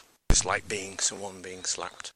Nightjars - Being Someone Being Slapped
Category: Sound FX   Right: Personal